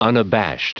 Prononciation du mot unabashed en anglais (fichier audio)
Prononciation du mot : unabashed